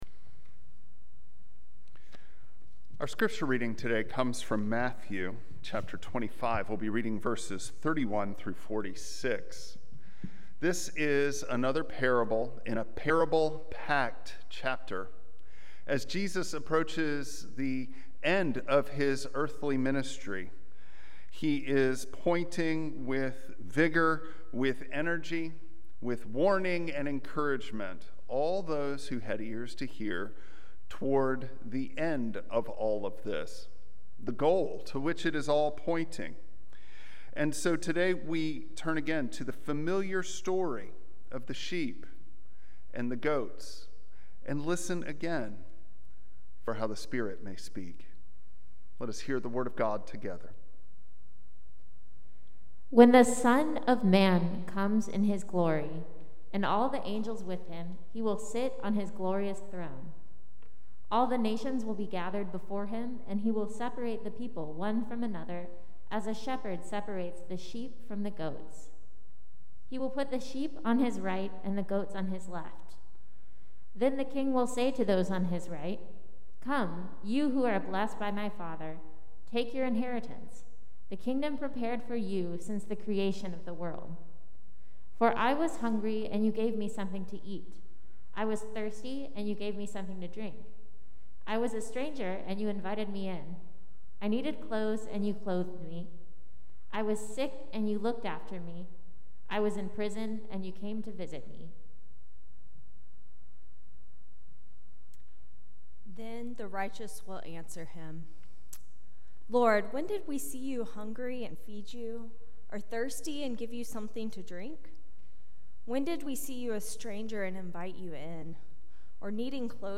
Matthew 25:31-46 Service Type: Traditional Service Bible Text